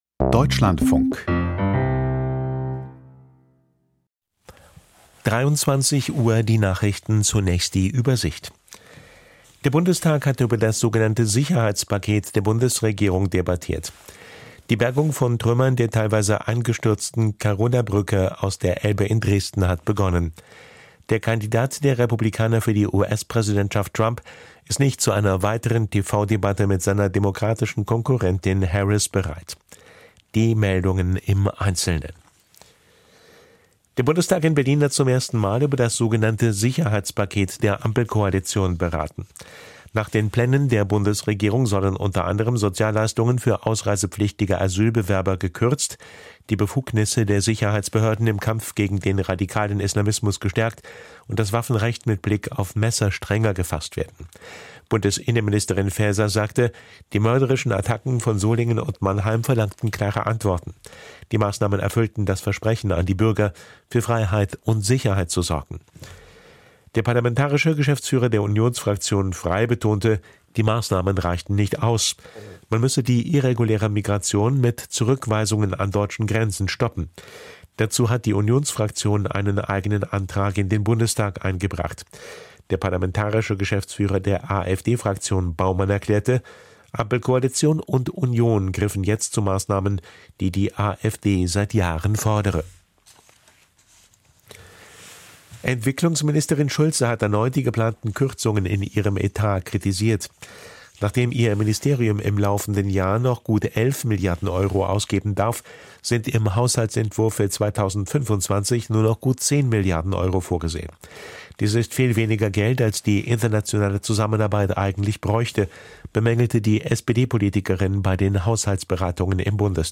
Wo stehen die Grünen? Interview Lamya Kaddor, innenpolitische Sprecherin - 12.09.2024